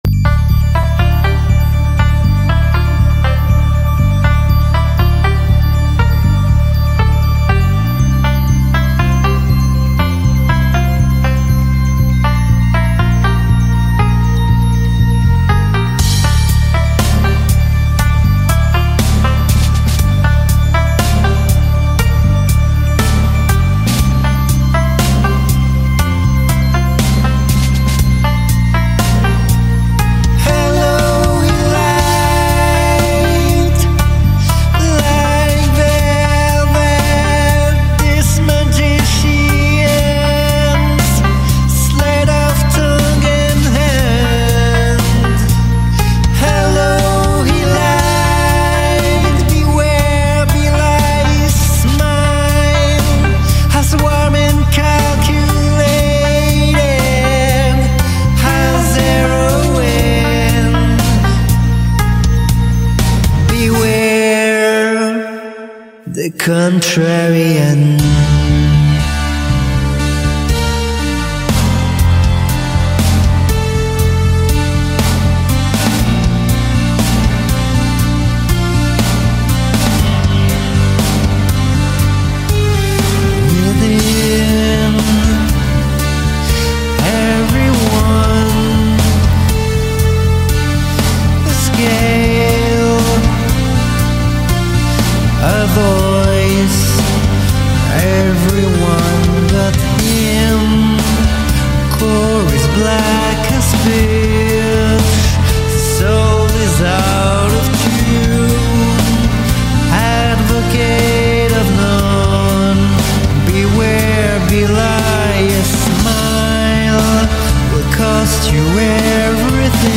Bandes-son
9 - 60 ans - Ténor